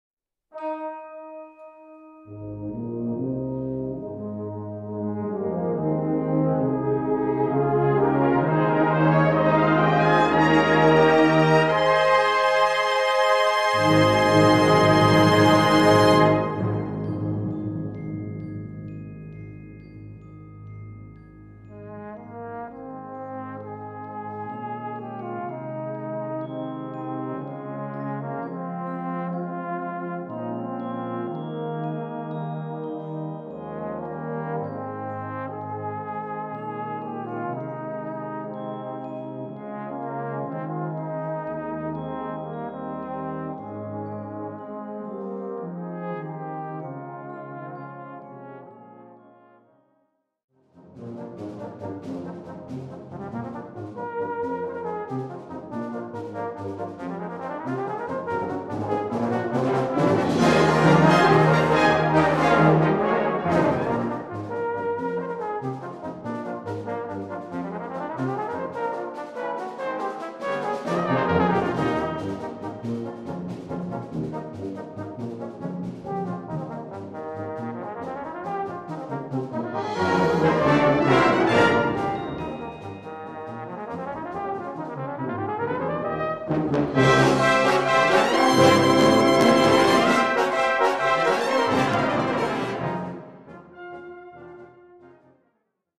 Cor et Brass Band